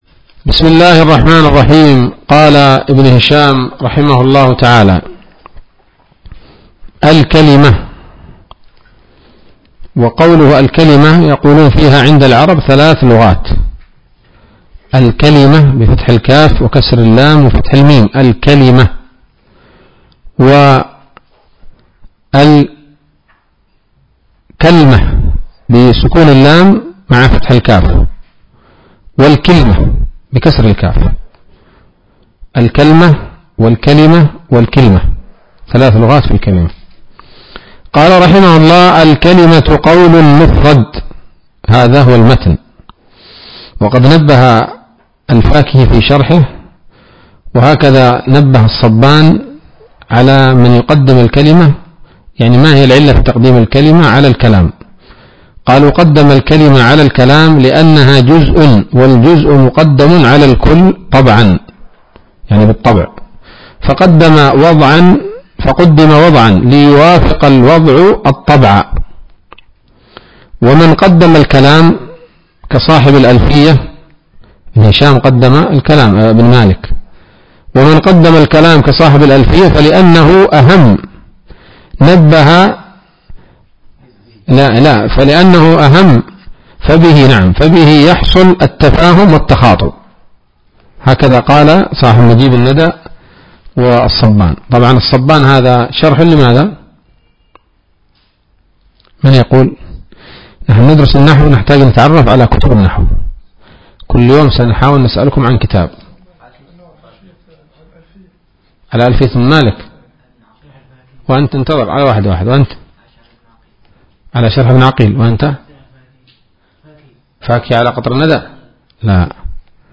الدرس الثاني من شرح قطر الندى وبل الصدى [1444هـ]